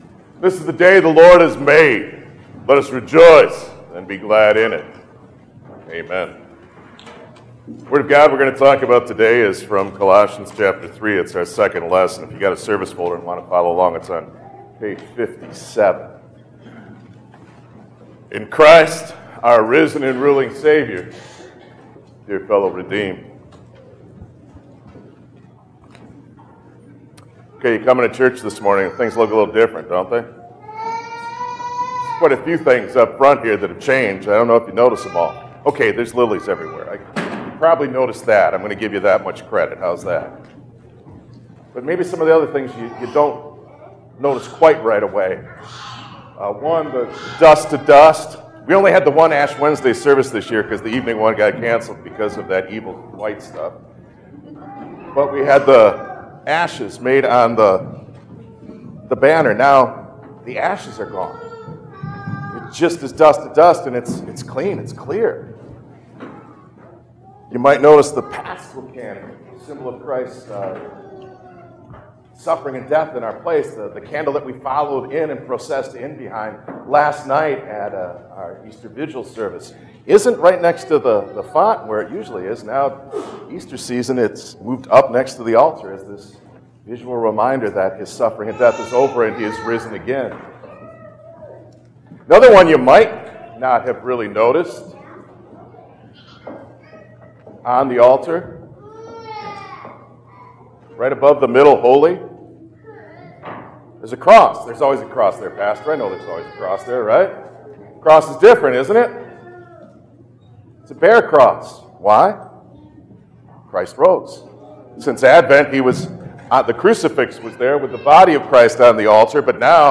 Series: Easter Festival